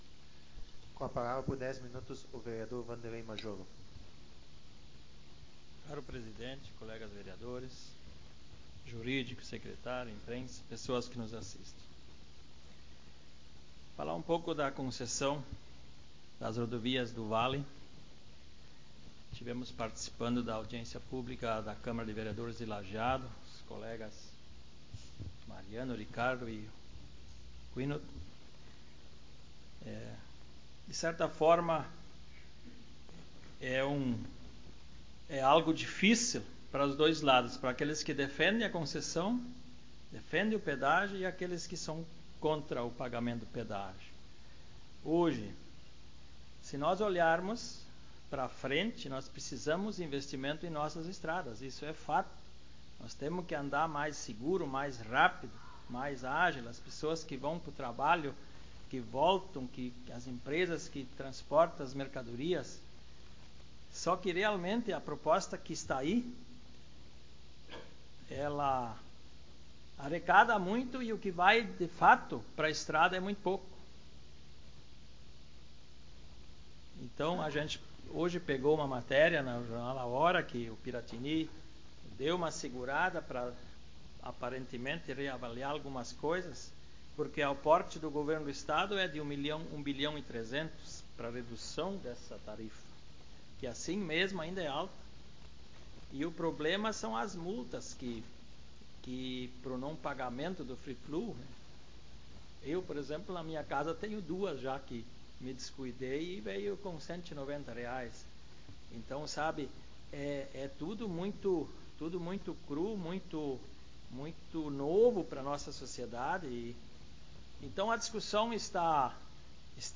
Modalidade: Áudio das Sessões Vereadores